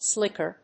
音節slíck・er 発音記号・読み方
/ˈslɪkɝ(米国英語), ˈslɪkɜ:(英国英語)/